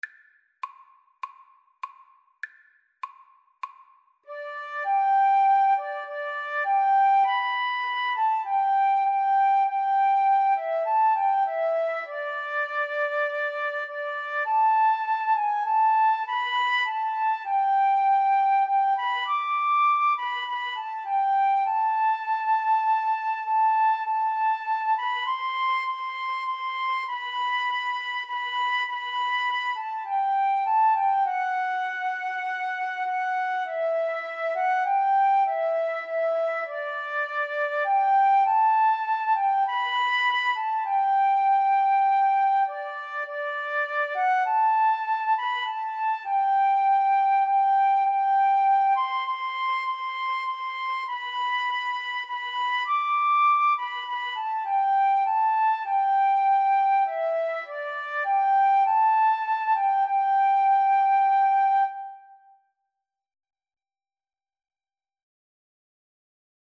4/4 (View more 4/4 Music)
Guitar-Flute Duet  (View more Easy Guitar-Flute Duet Music)
Classical (View more Classical Guitar-Flute Duet Music)